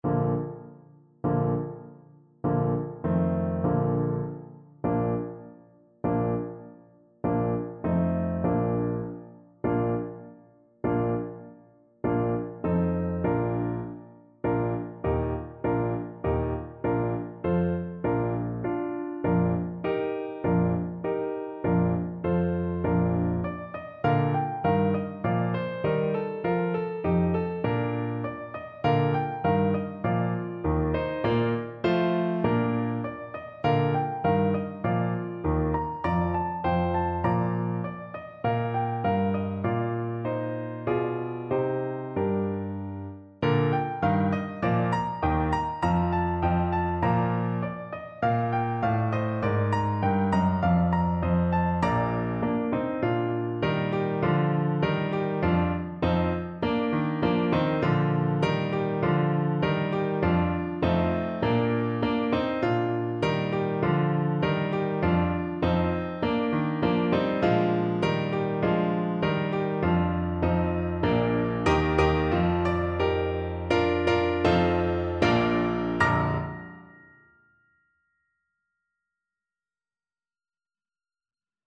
Trombone
Bb major (Sounding Pitch) (View more Bb major Music for Trombone )
Moderato
2/4 (View more 2/4 Music)
Traditional (View more Traditional Trombone Music)
world (View more world Trombone Music)
Argentinian